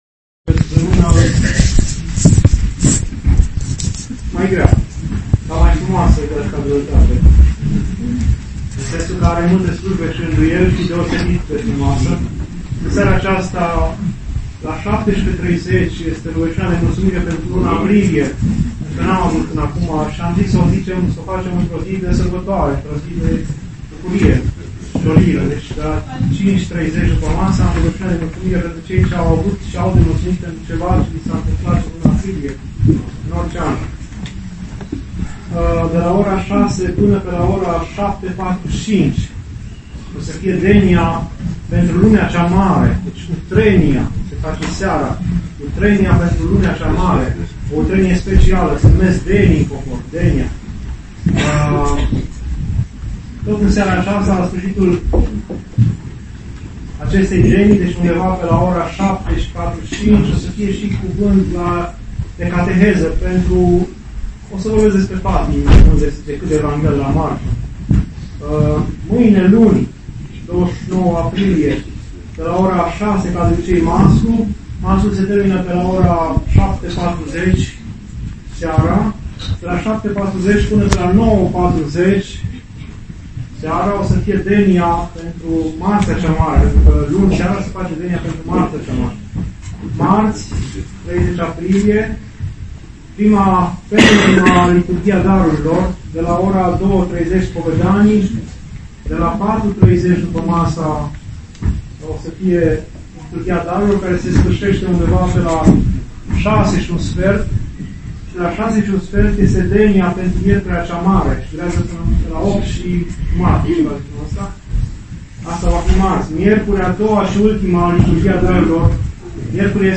Anunțuri